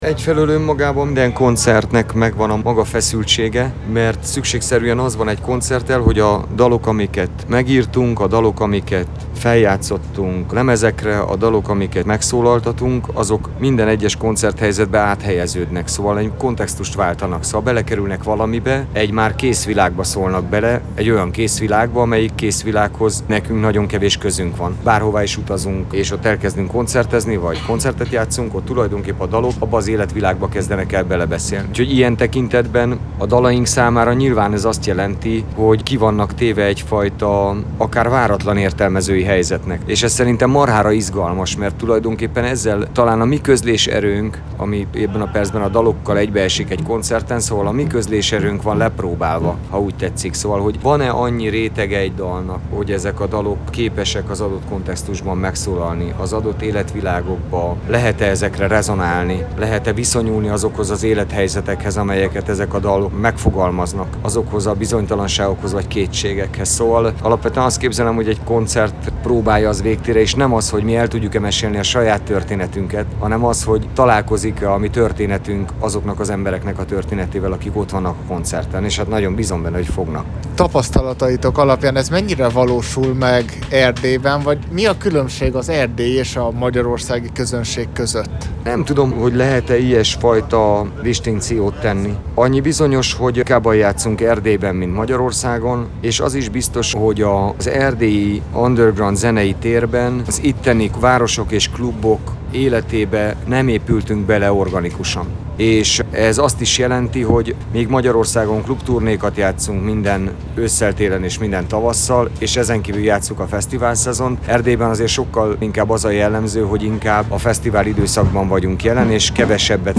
a NyárON Szereda fesztiválon beszélgettünk